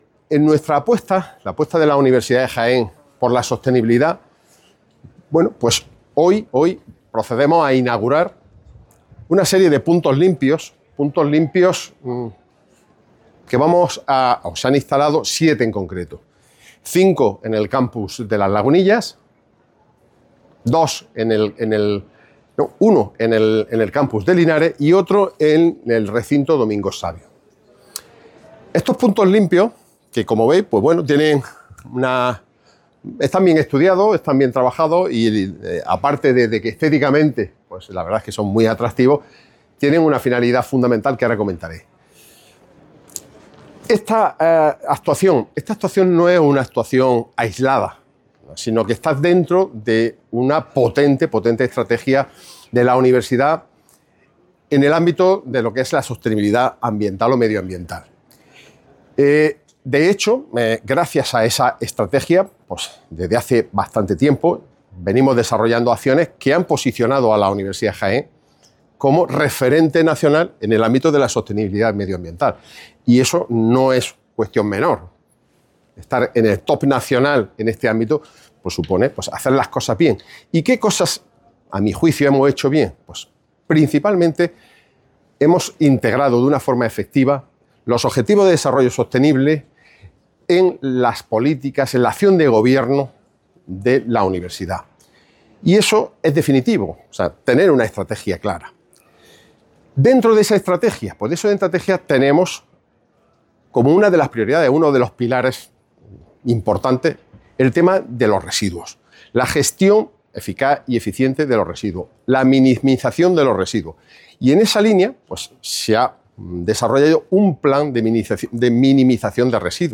declaraciones_Rector_puntos_limpios.mp3